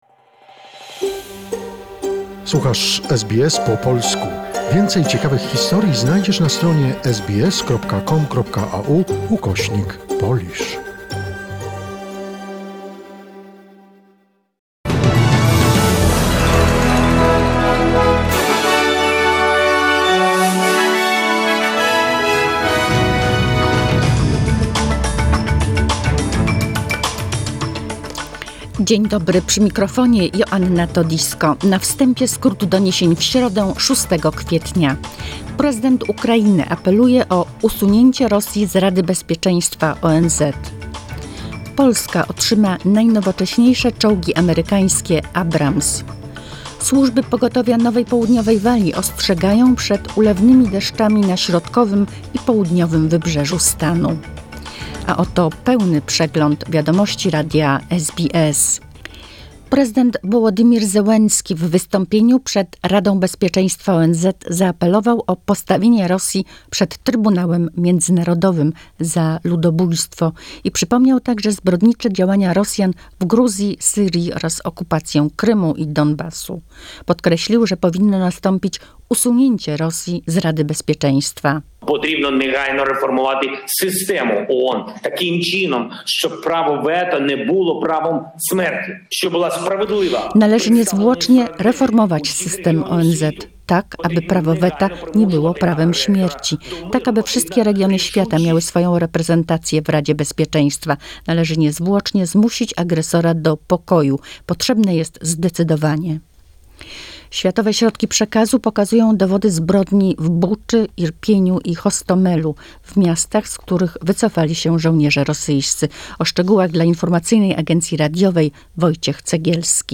SBS News in Polish, 6 April 2022